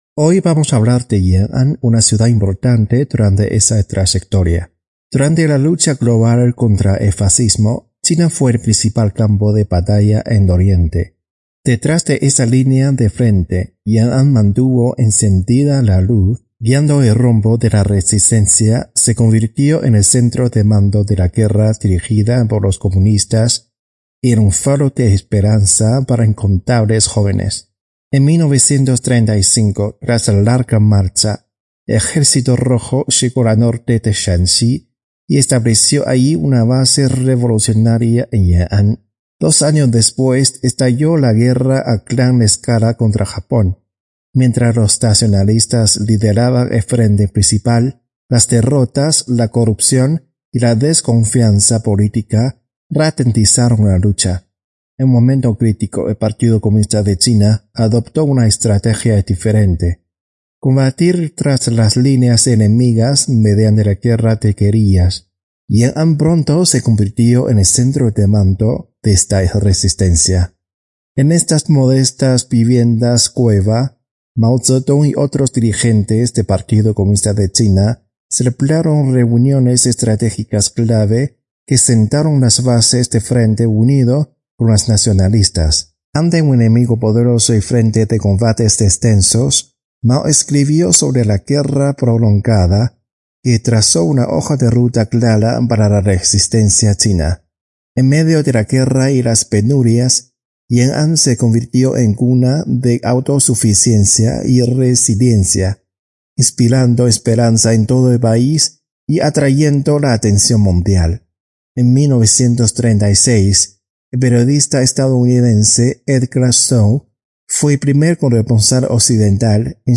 Estos 4 episodios fueron emitidos en nuestro programa radial, Clave China, los días 17, 24 y 31 de Agosto y 7 de septiembre del 2025